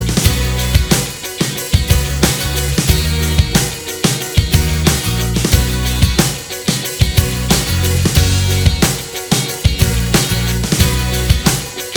Minus Distortion Guitars Pop (2010s) 3:05 Buy £1.50